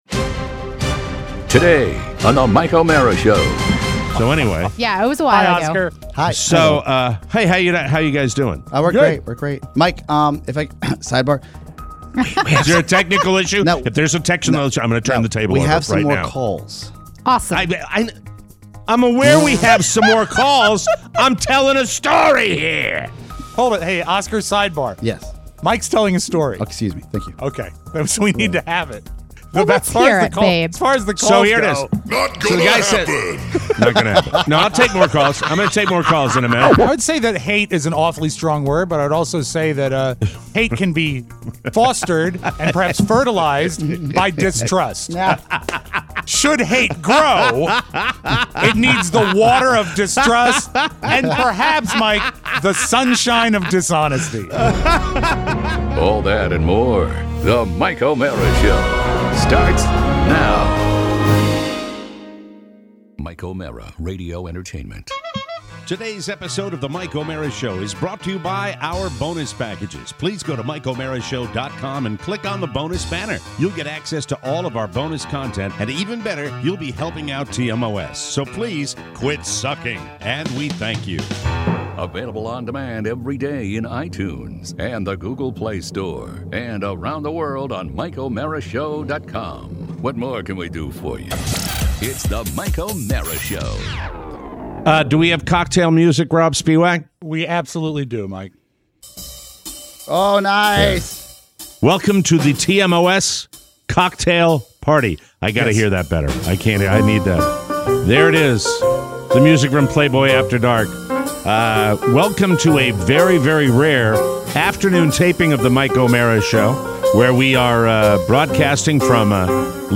It’s the first TMOS Cocktail Party! We take your calls